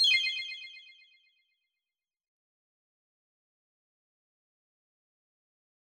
confirm_style_4_echo_006.wav